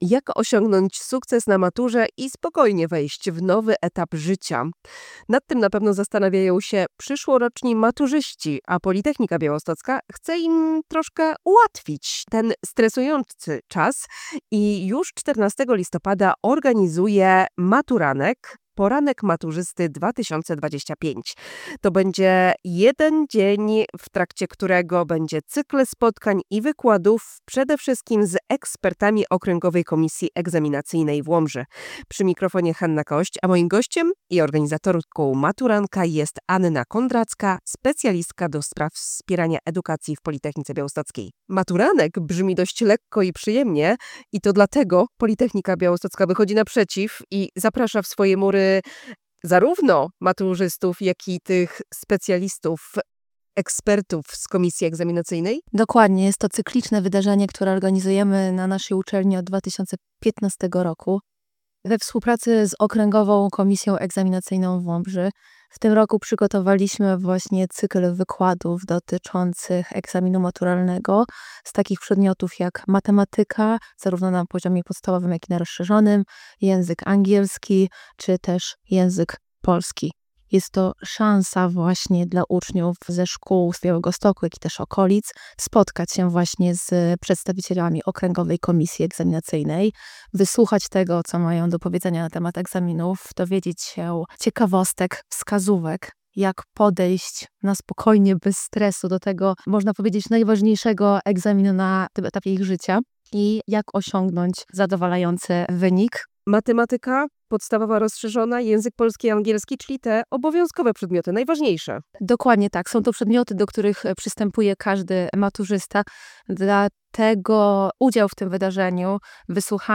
Zapowiedź